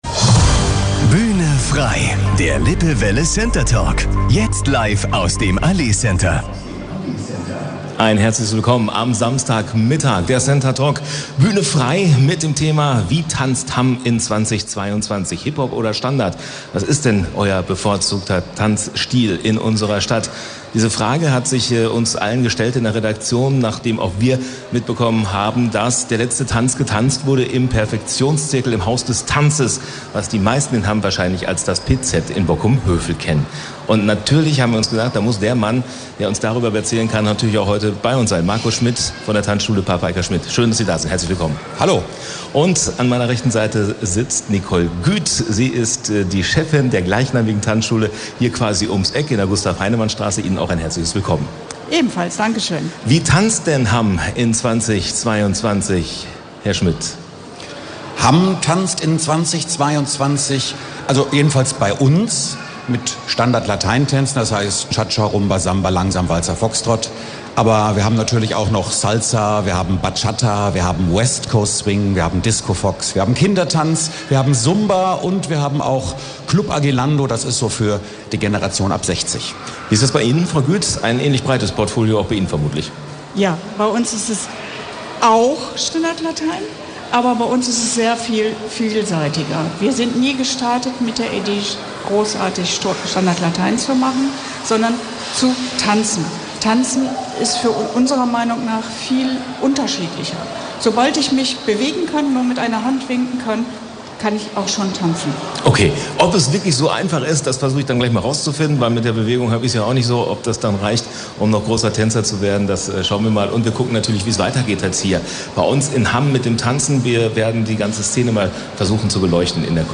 in unserer Live-Sendung Bühne Frei im Allee Center gesprochen.